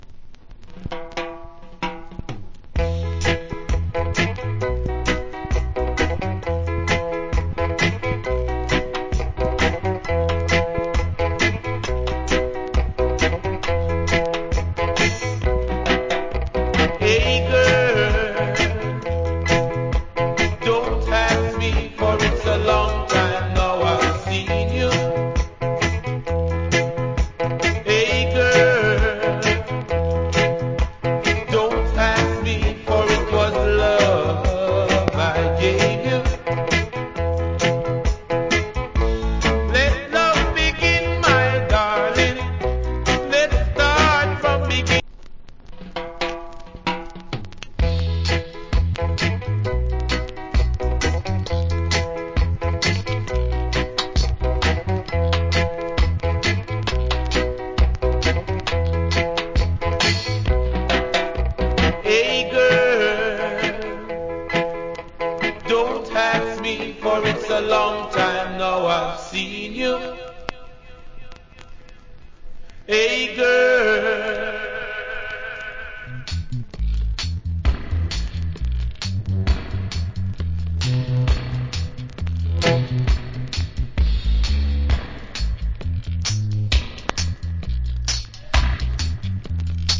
Cool Vocal.